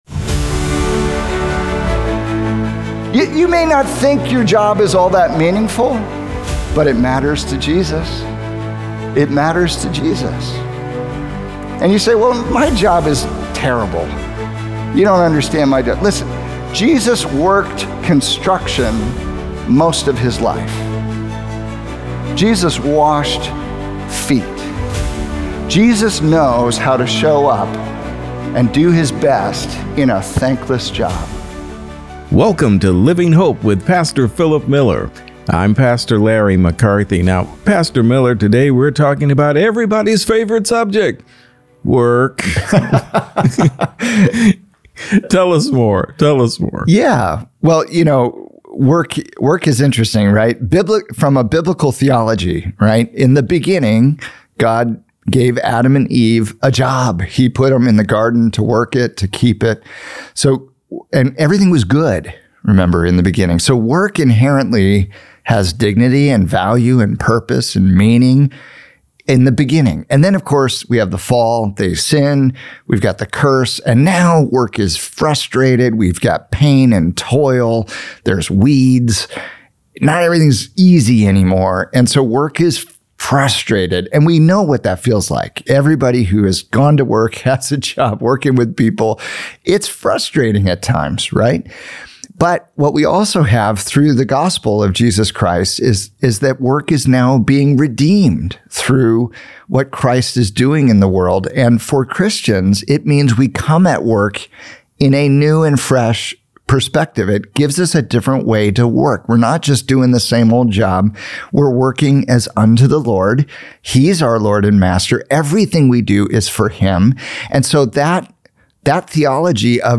Dignifying Your Daily Labor in the Presence of Christ | Radio Programs | Living Hope | Moody Church Media